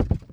FallImpact_Wood 01.wav